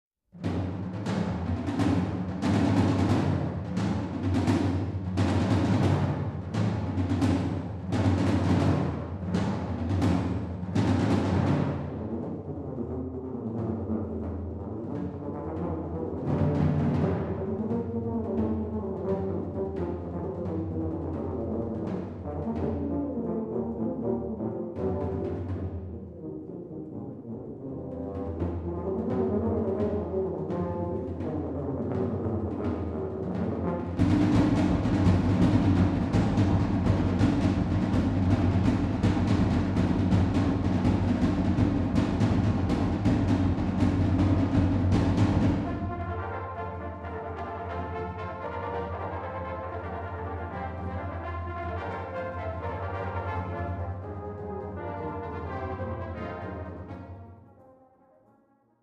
Recueil pour Batterie